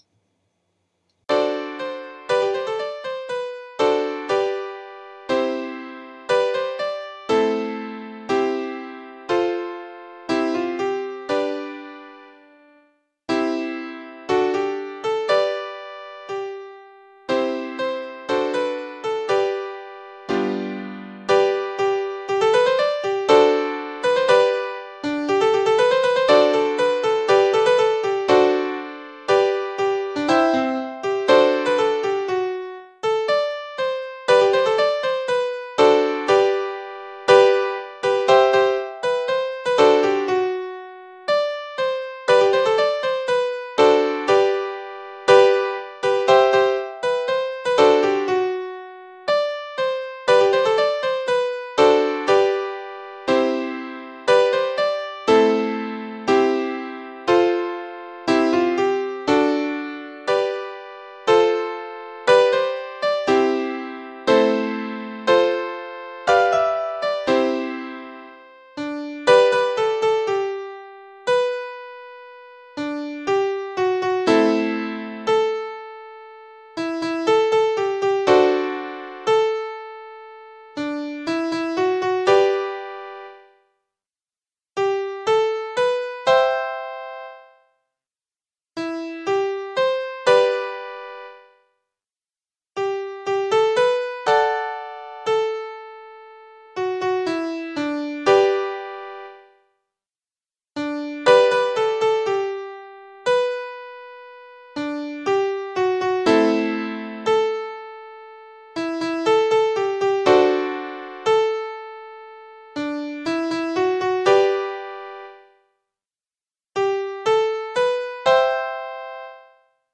CHOGM3bpm120.mp3